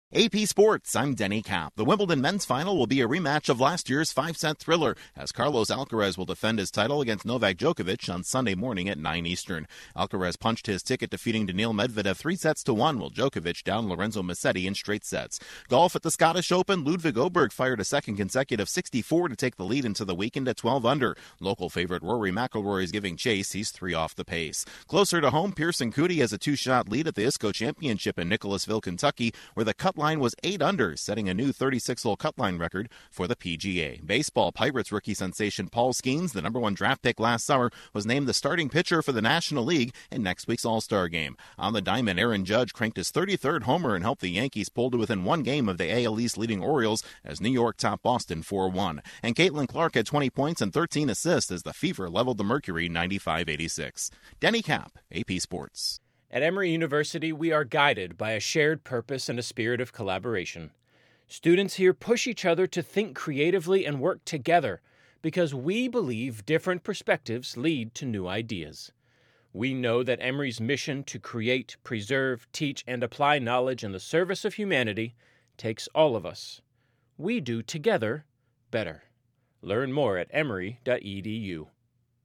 ((NOTE pronunciation of Aberg is OH'-burg))